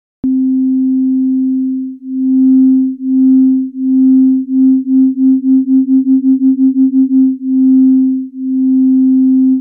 Easiest example is set two oscillators to sine waves, mix them both at the same volume, and then simply increase the detune amount on one of the oscillators. You get amplitude modulation where the waveforms sum and cancel each other out.
SineBeating.mp3